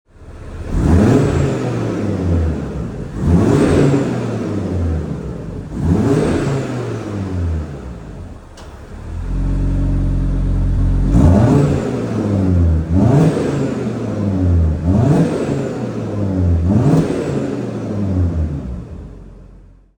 LISTEN TO IT'S SWEET SYMPHONY
• RS Sports Exhaust System with Black Oval Tips
Kyalami-Green-rs3-revs.mp3